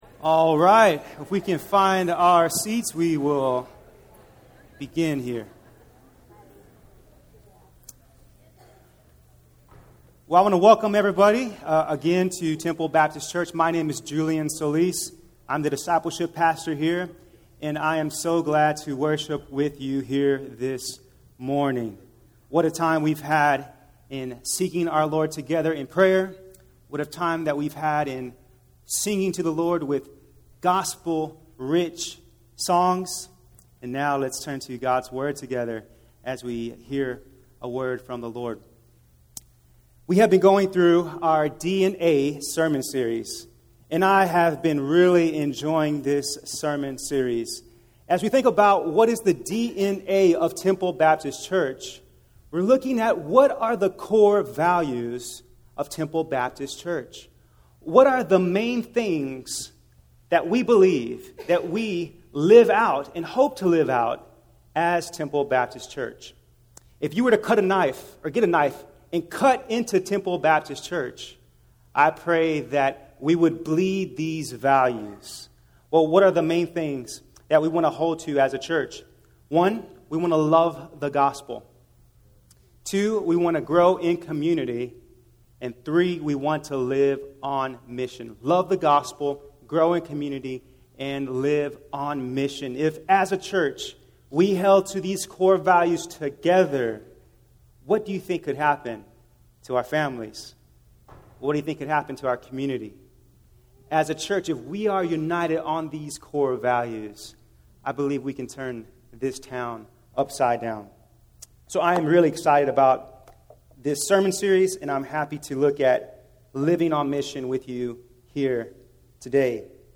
Temple-Sermons-1.mp3